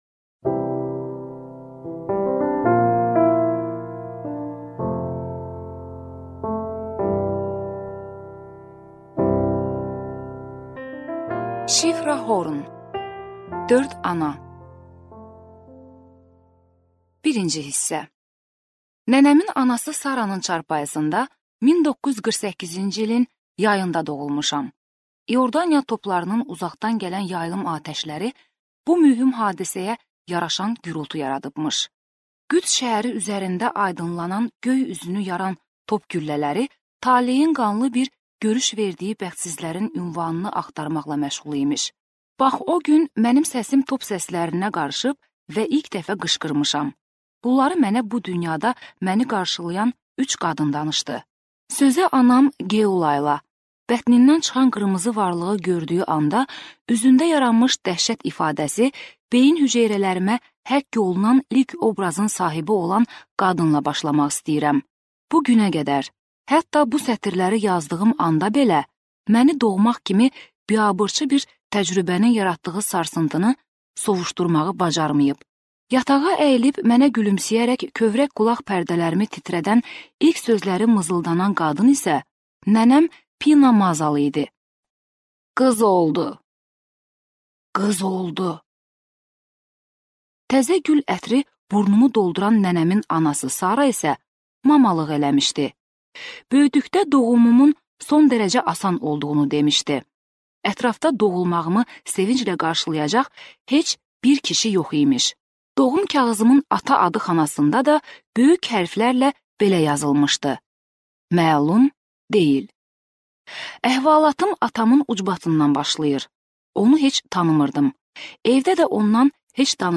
Аудиокнига Dörd ana | Библиотека аудиокниг